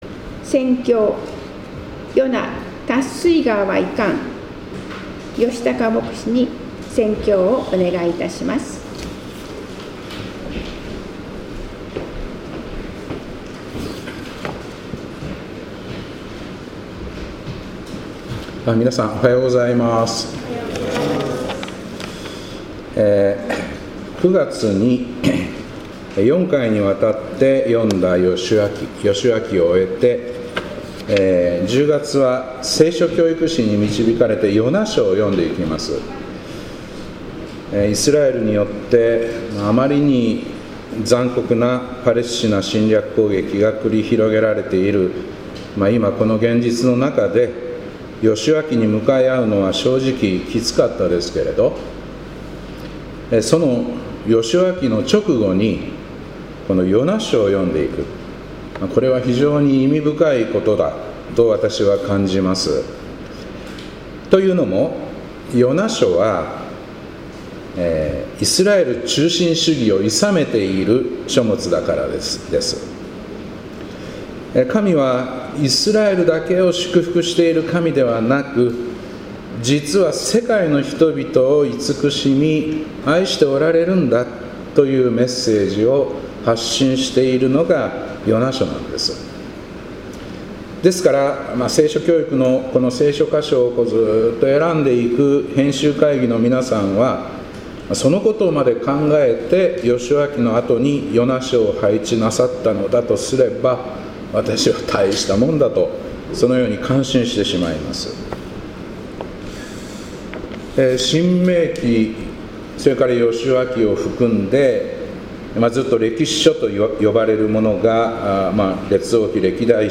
2025年10月5日礼拝「ヨナ、たっすいがーはいかん」